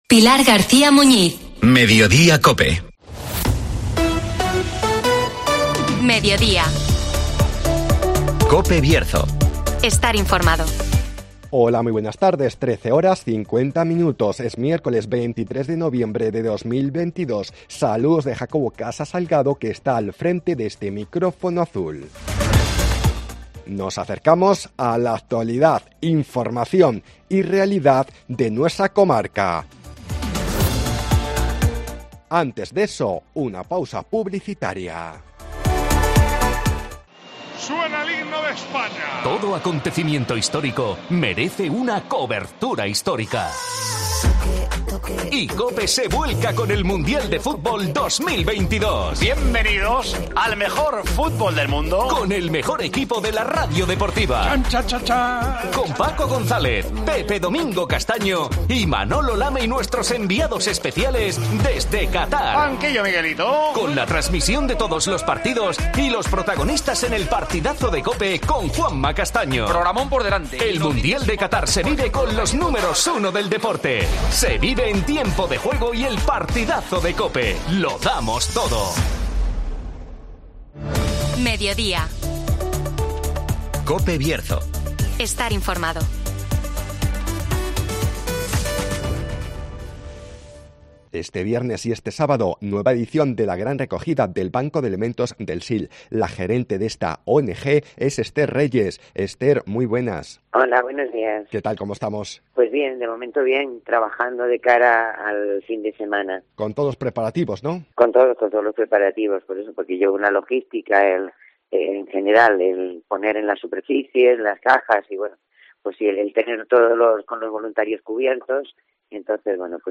La 'Gran Recogida' del Banco de Alimentos del Sil espera superar récords este viernes y sábado (Entrevista